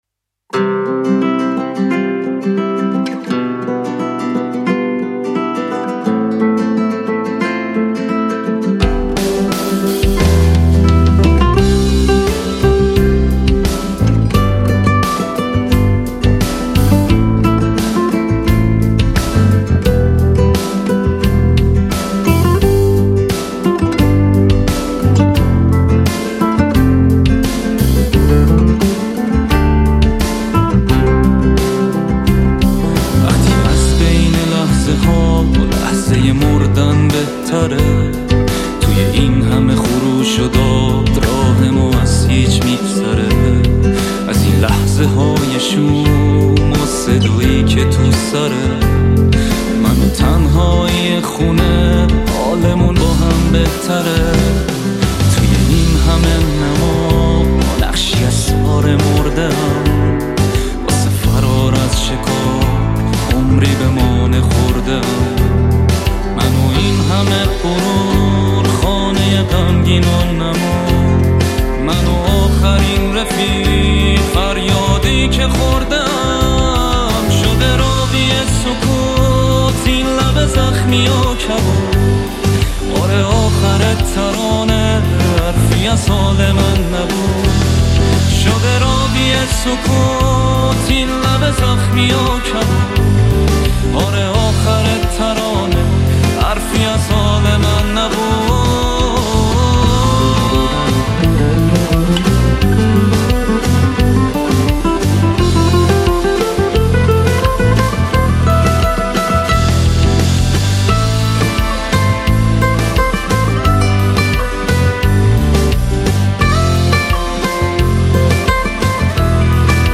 صدایی بسیار زیبا و ریتم بسیار باحال👏👏👍👍😍